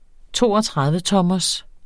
Udtale [ ˈtoʌtʁaðvəˌtʌmʌs ]